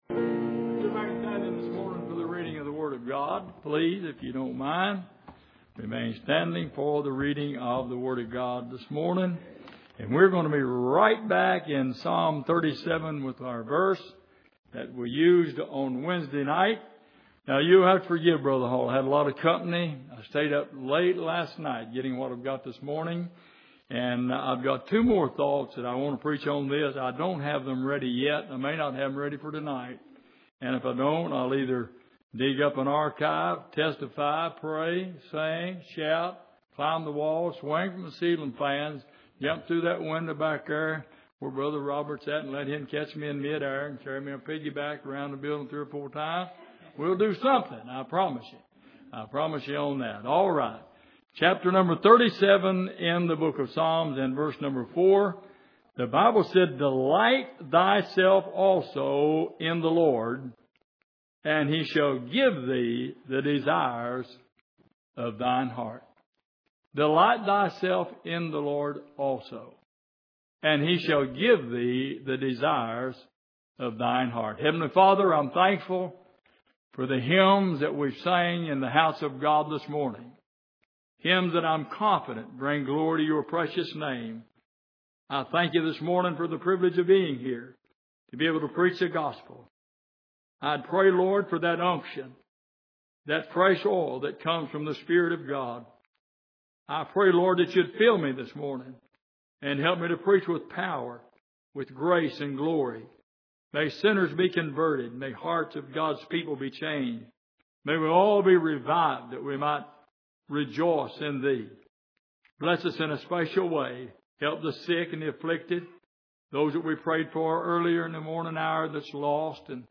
Exposition of the Psalms Passage: Psalm 37:4 Service: Sunday Morning Delight and Desires